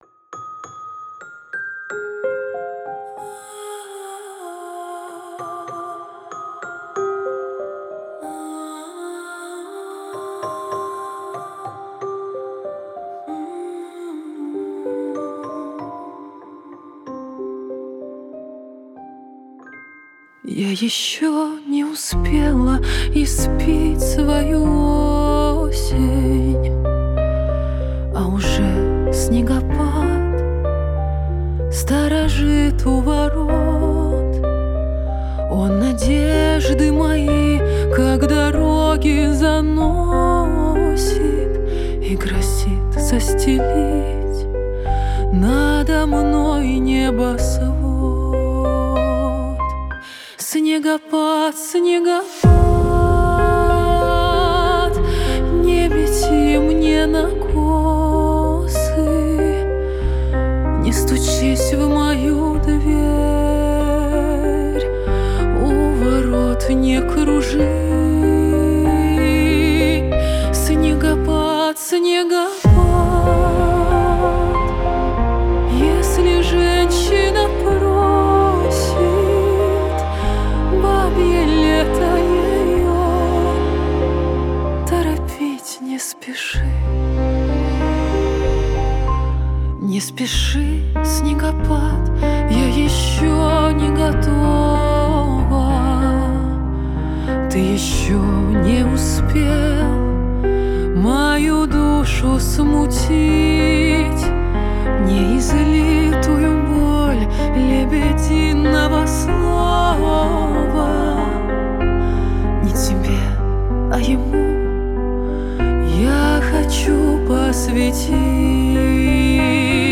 Рейв